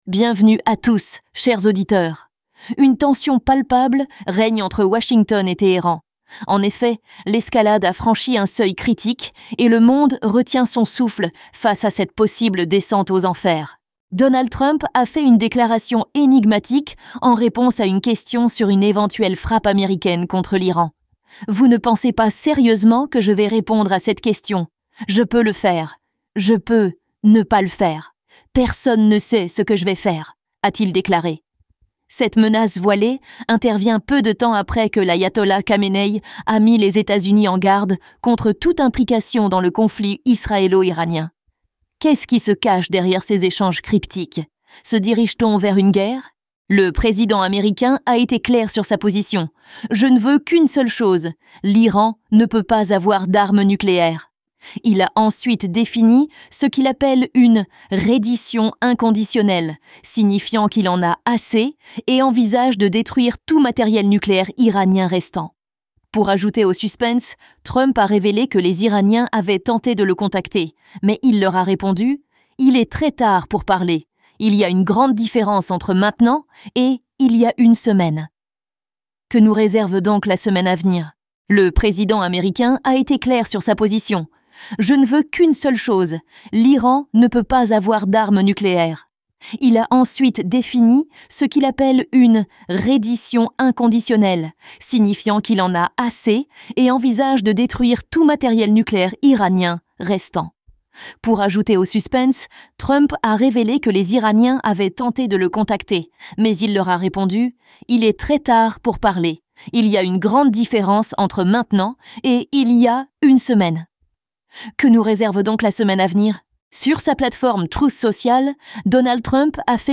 PODCASTS – RADIOTAMTAM AFRICA Radio TAMTAM AFRICA Chronique : La Chine et la Russie s’allient sur l’Iran Chronique : La Chine et la Russie s’allient 21 juin 2025